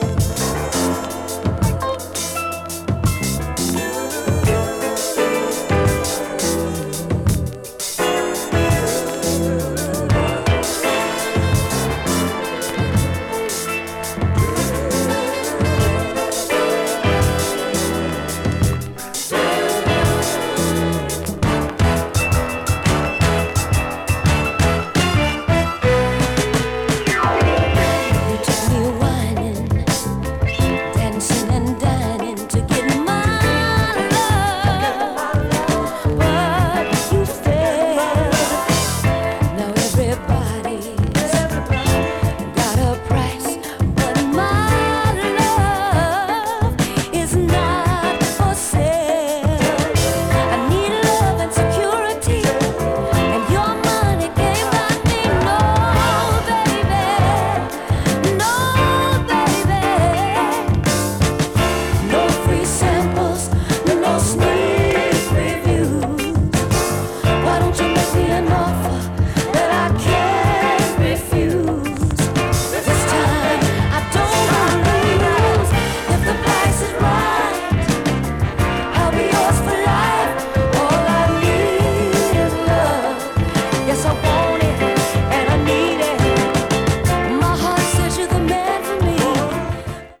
70s MELLOW SOUL / FUNK / DISCO 詳細を表示する